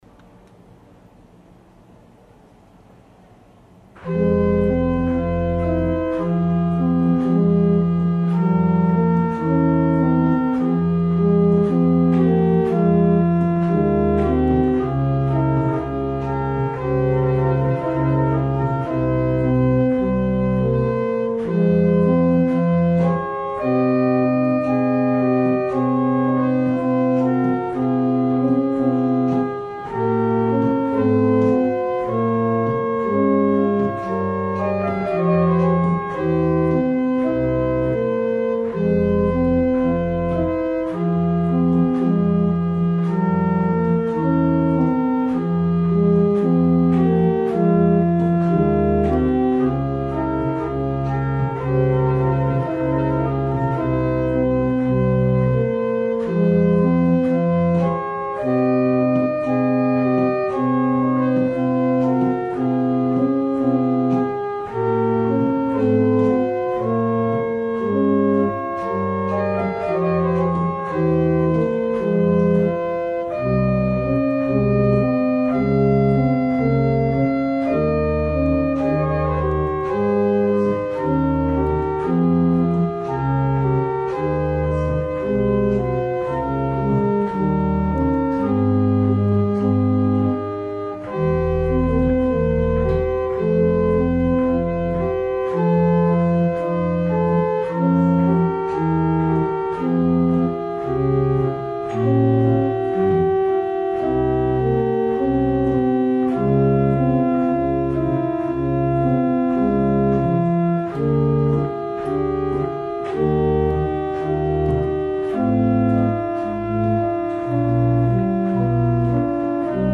L'Organo Rotelli-Varesi del Monastero di S.Gregorio Armeno- Napoli
Temperamento: Equabile
La registrazione è amatoriale e ha il solo scopo di presentare qualche caratteristica fonica dello strumento.